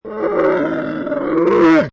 Camel 6 Sound Effect Free Download